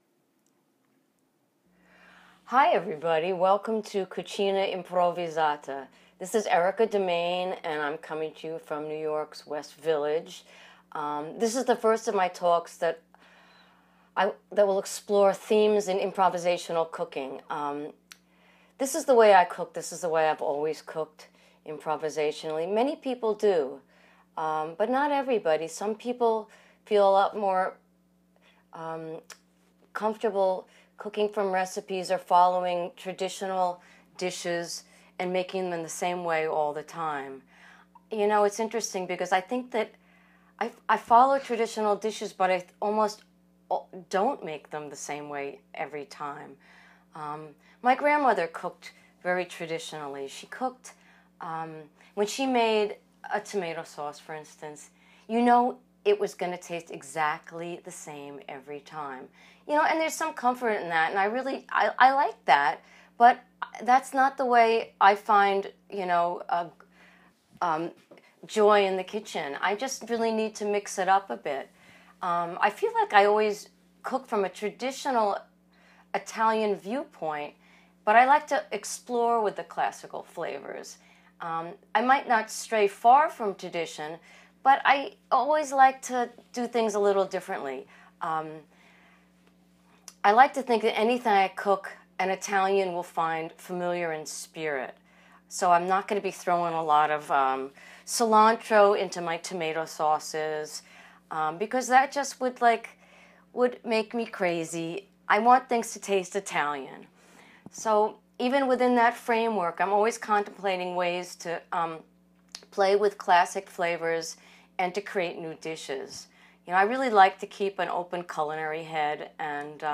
I’m going to be posting talks that explore seasonal themes in improvisational cooking. The subject of this one is the last of the summer’s tomatoes, and what to do with them.